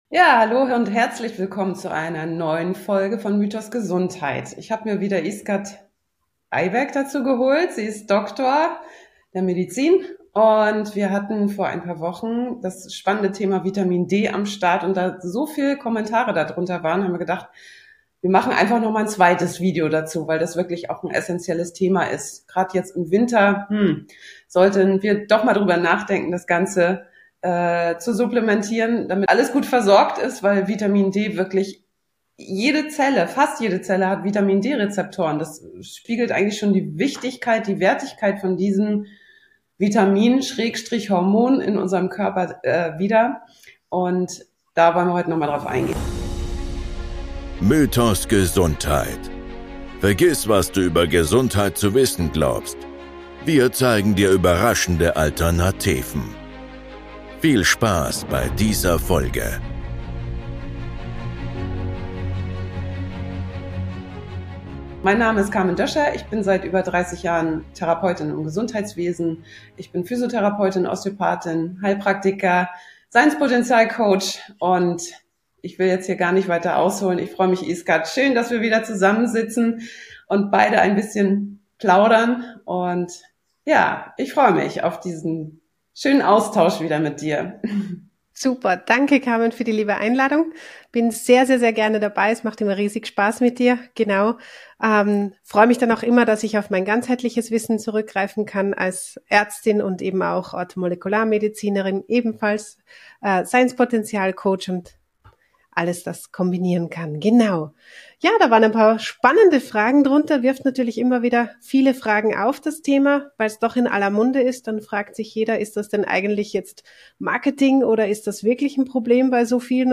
Die Wahrheit über Vitamin D (Teil 2) - Interview